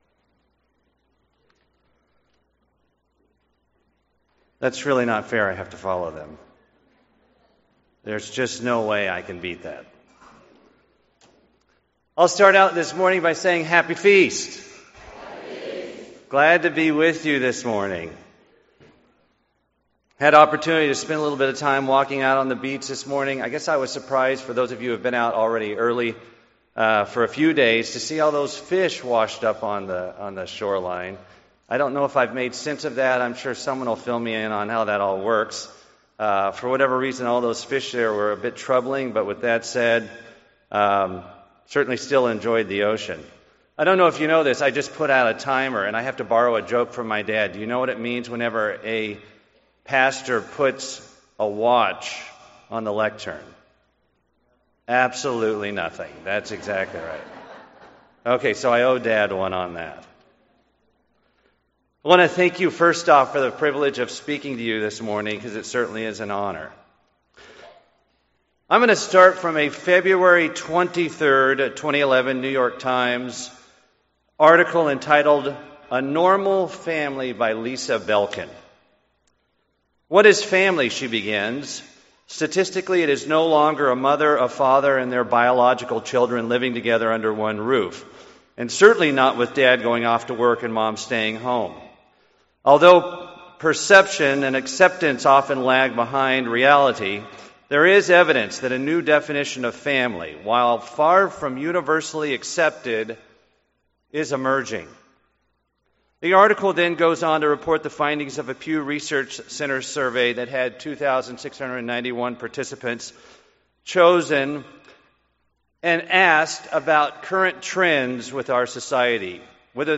This sermon was given at the Panama City Beach, Florida 2015 Feast site.